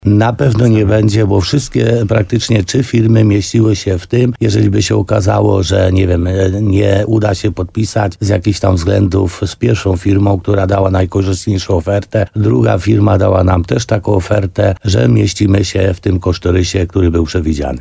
Jak zapewnia wójt gminy Laskowa Piotr Stach, cena na pewno nie będzie wyższa, pomimo tego, że przetarg dopiero będzie rozstrzygnięty.